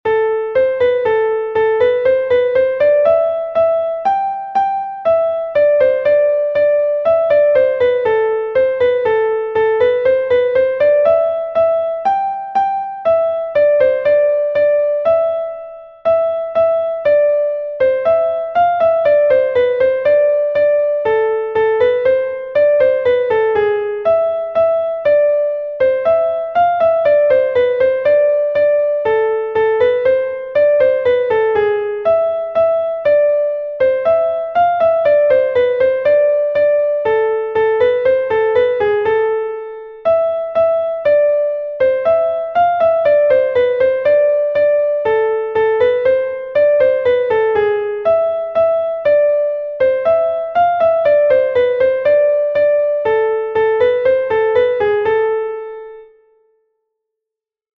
Kas a-barh from Brittany